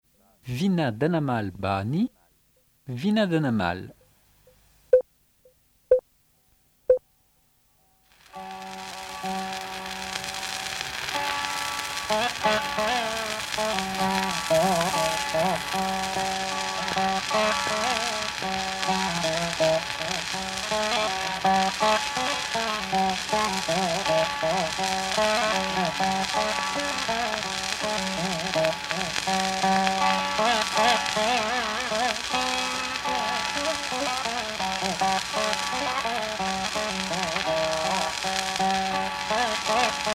Musique carnatique